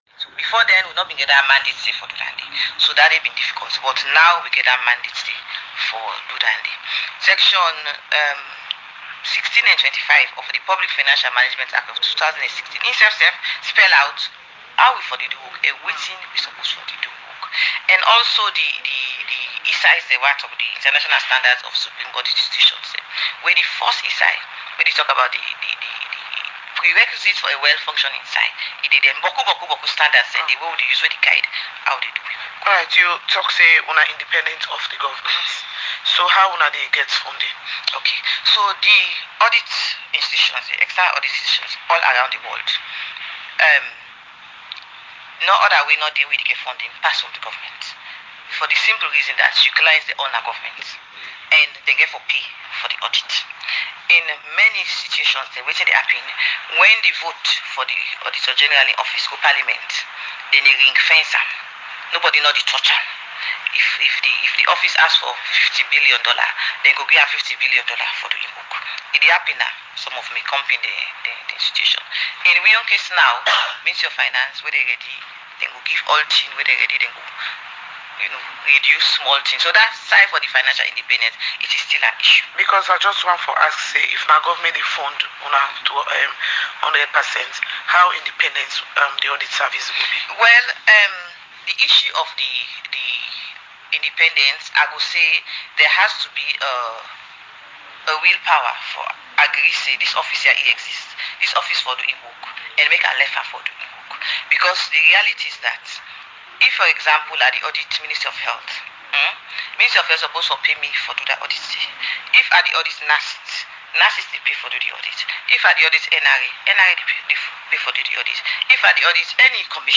Sierra-Leone-Audit-report-debate-rdaio-98.1-FM-1.mp3